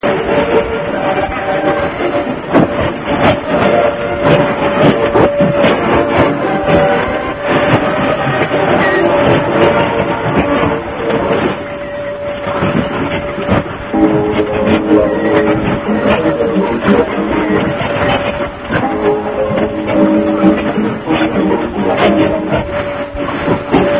80-90年代の60 meter band リスト